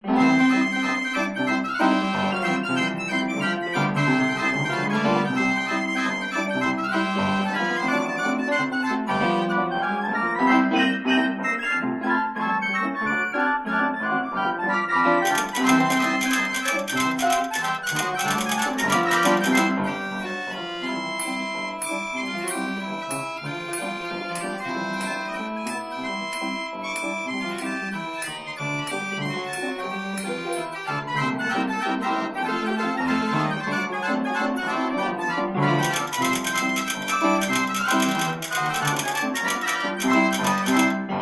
coin-in-the-slot pianos
Fox Trot